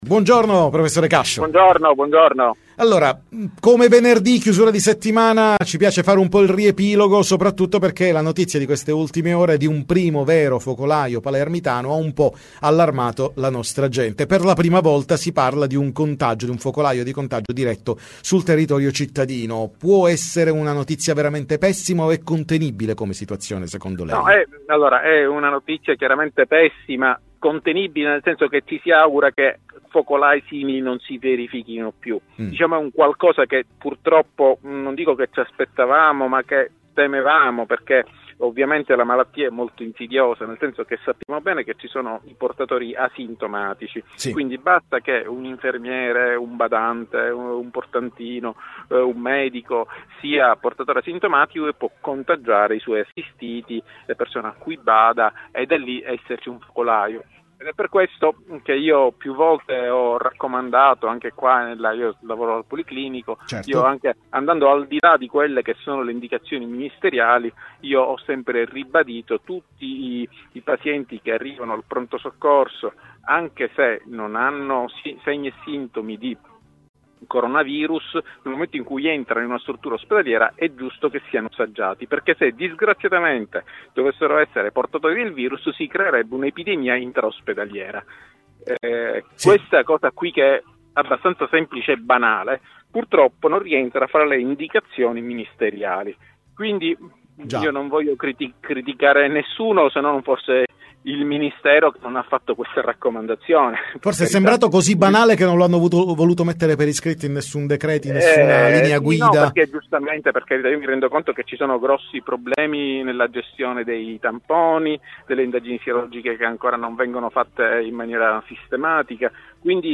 Time Magazine intervista